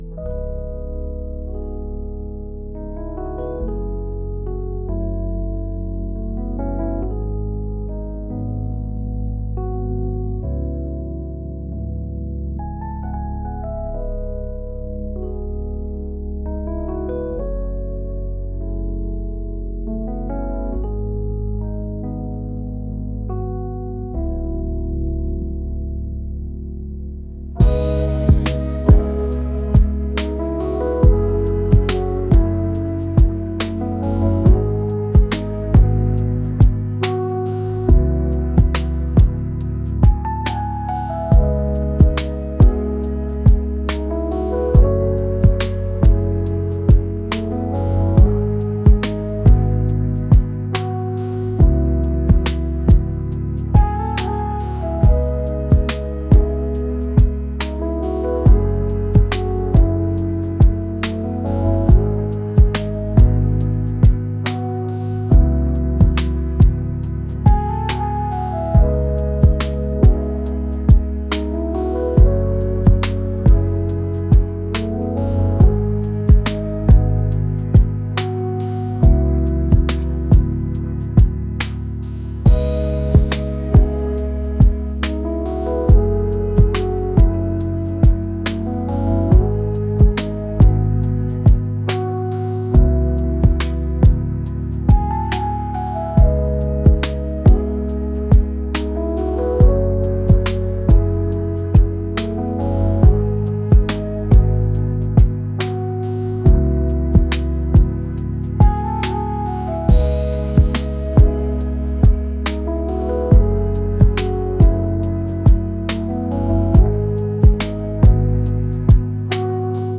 soul & rnb | ambient | laid-back